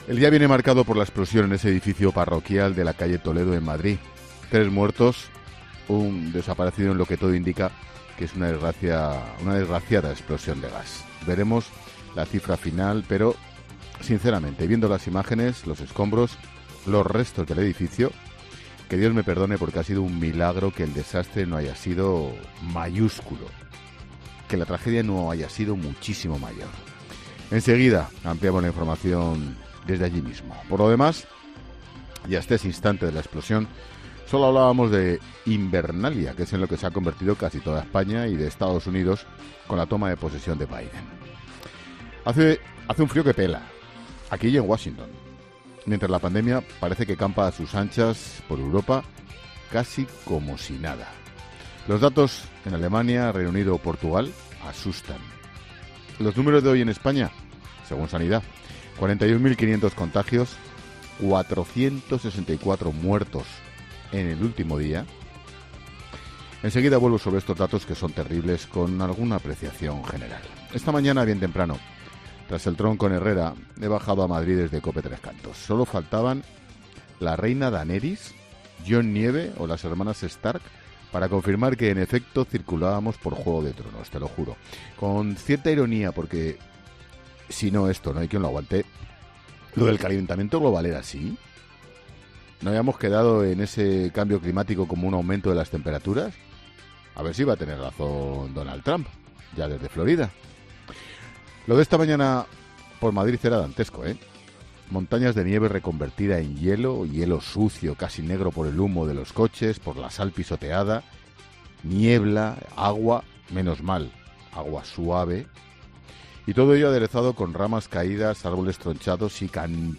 Monólogo de Expósito
El director de 'La Linterna', Ángel Expósito, analiza las principales noticias de este miércoles